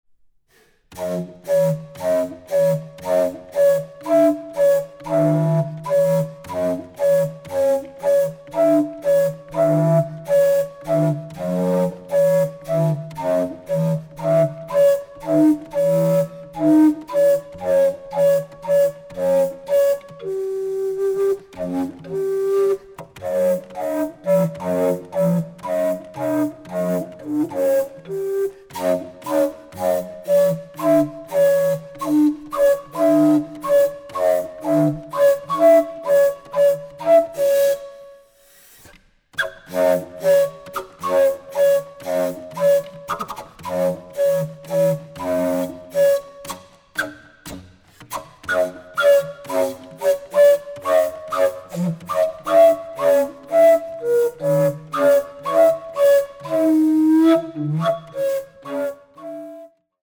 Recording: Deutschlandfunk Kammermusiksaal, Köln, 2023 + 2024